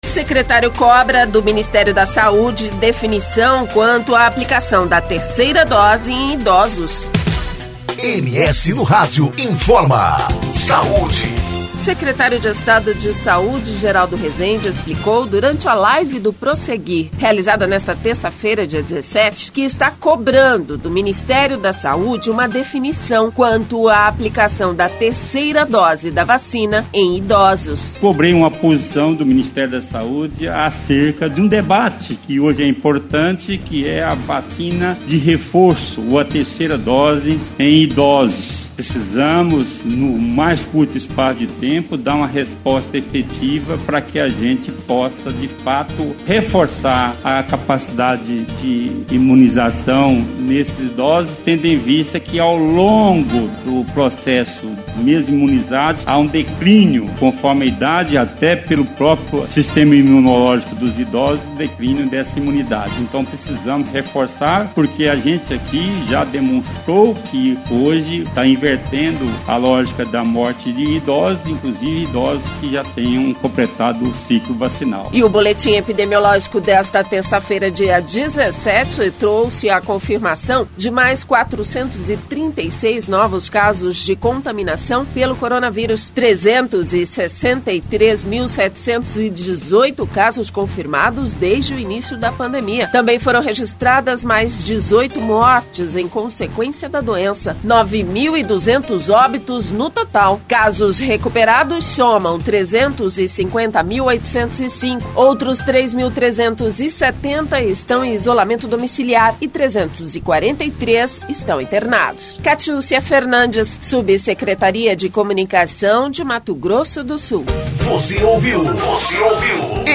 O secretário de estado de saúde, Geraldo Resende, explicou durante a live do Prosseguir, realizada nesta terça-feira, dia 17, que está cobrando do Ministério da Saúde uma definição quanto à terceira dose da vacina em idosos.